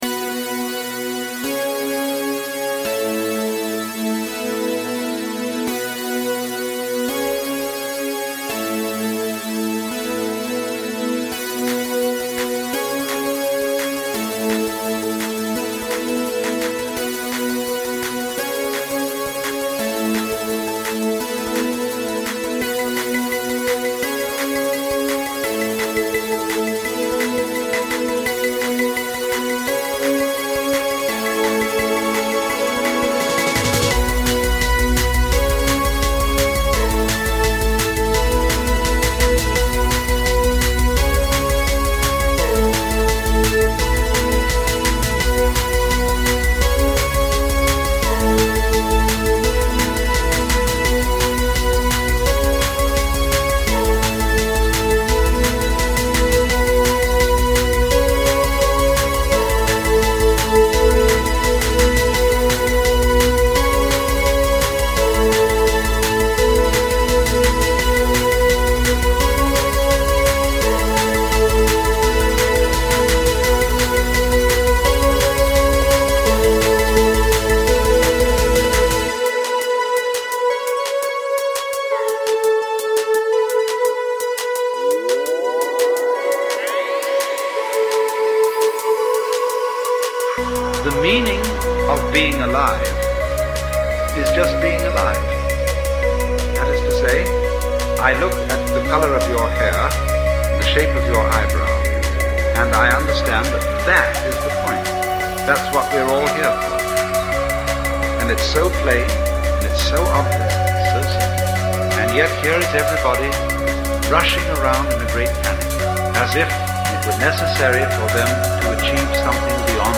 It’s synthwave with a bit of punk and post-punk thrown in.